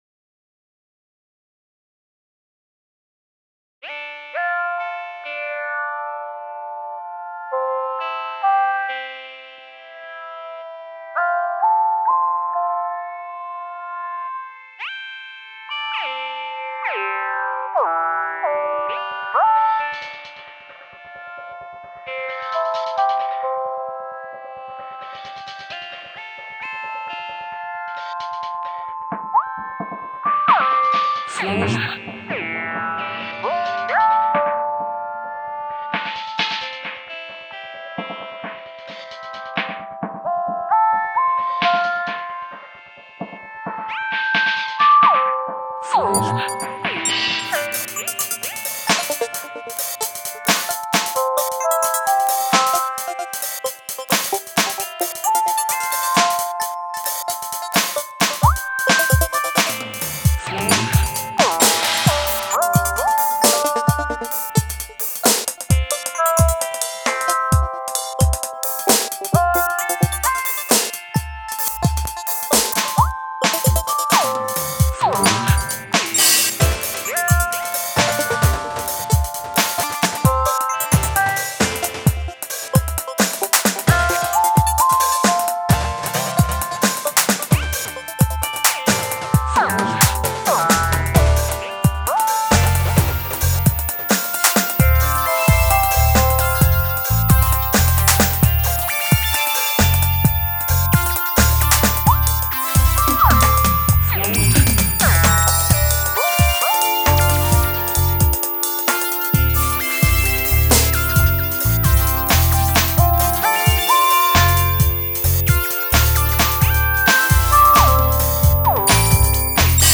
Genre Dub